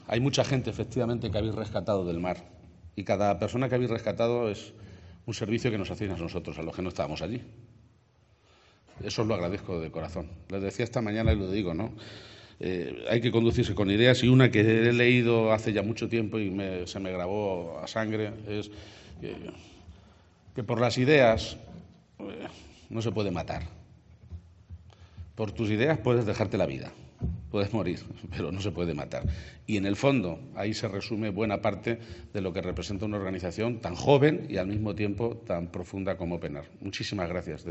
El presidente García-Page ha realizado estas declaraciones durante la entrega del Premio ´Abogados de Atocha´, que promueve Comisiones Obreras Castilla-La Mancha (CCOO-CLM) y que ha recaído en la organización humanitaria Open Arms, dedicada al rescate y protección de las personas abandonadas en aguas internacionales.